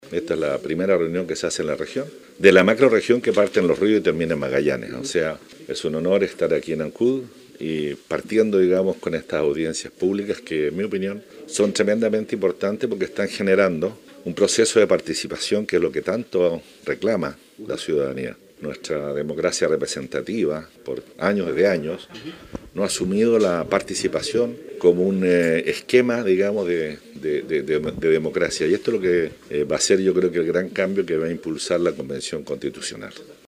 En tanto, el constituyente Harry Jürgensen destacó y precisó que es la primera reunión que se realiza en la macrozona sur del país